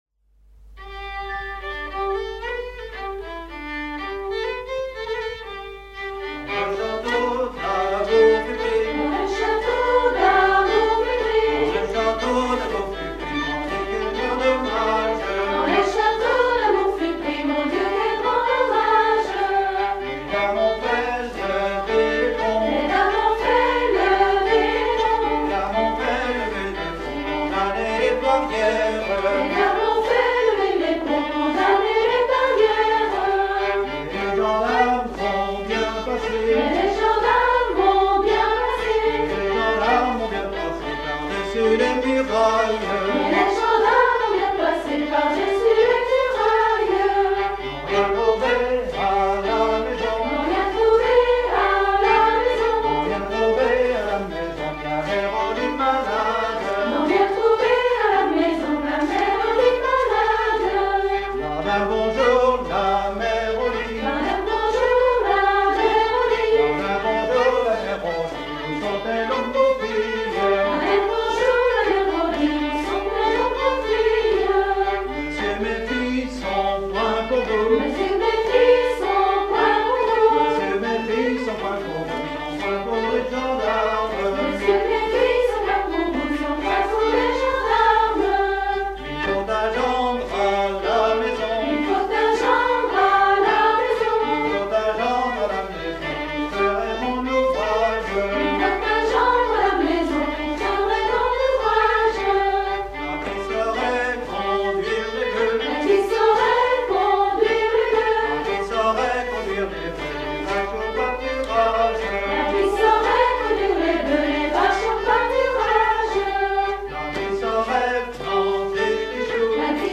danse : ronde : demi-tour
Genre laisse
Dix danses menées pour des atelirs d'apprentissage
Pièce musicale inédite